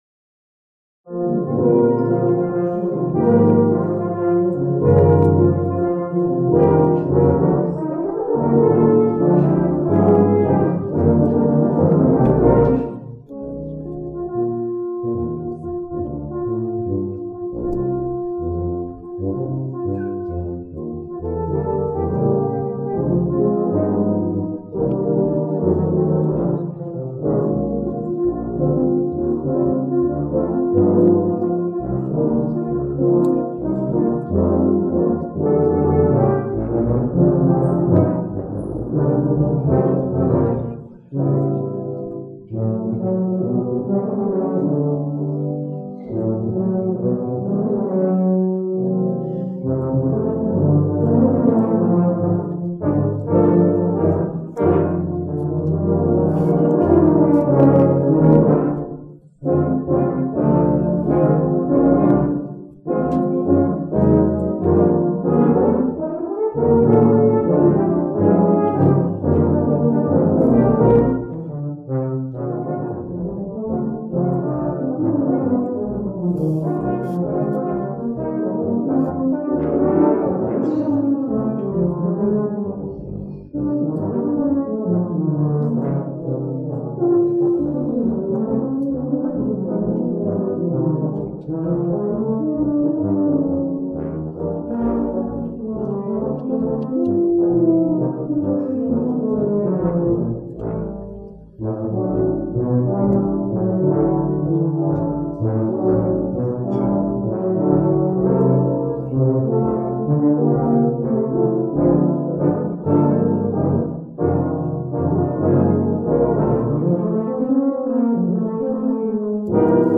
The sound quality is not the best since I was sitting 5 rows behind them on the stage, but it should give you a good idea.
12-tet
Tubas
Euphoniums
Winter_Wonderland-LATubaChristmas2006.mp3